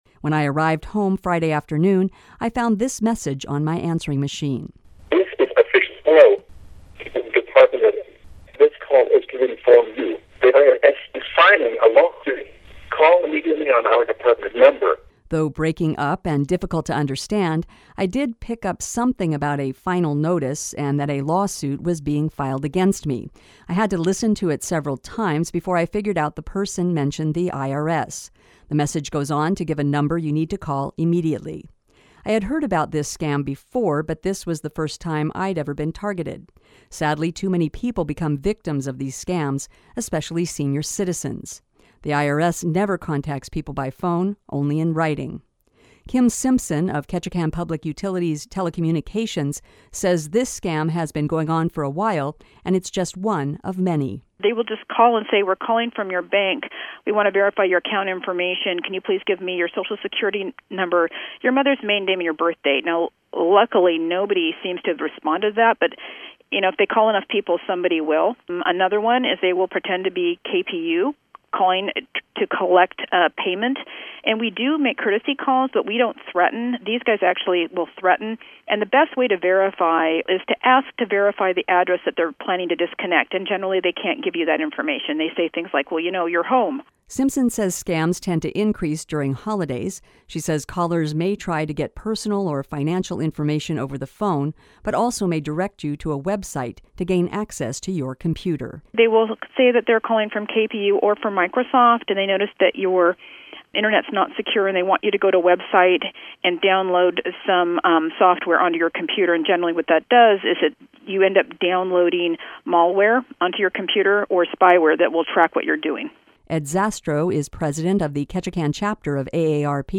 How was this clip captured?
When I arrived home Friday afternoon, I found this message on my answering machine. (inaudible message) Though breaking up and difficult to understand, I did pick up something about a “final notice” and that a “lawsuit” was being filed against me.